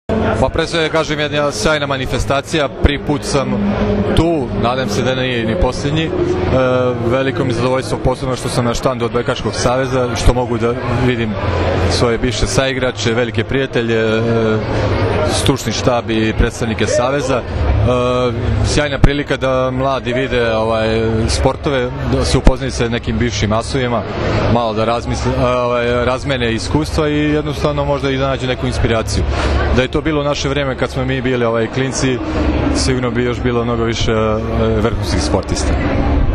IZJAVA ĐULE MEŠTERA